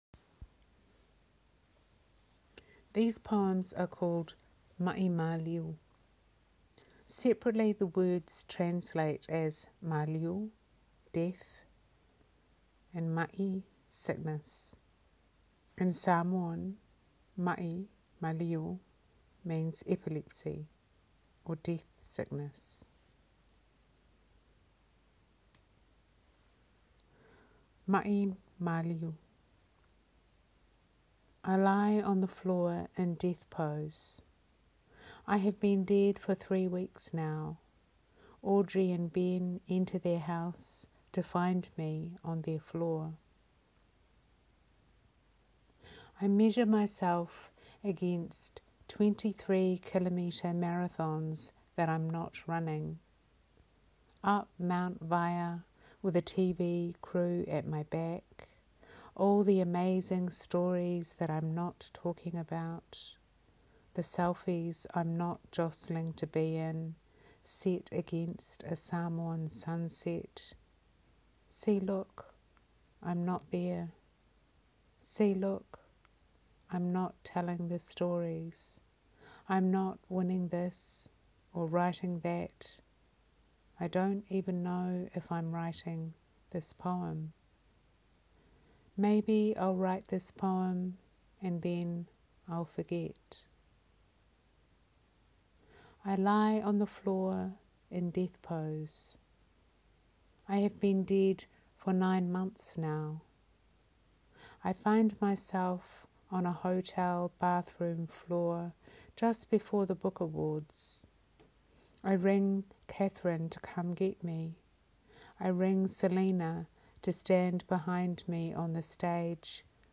Poetry Shelf Audio Spot – a mini performance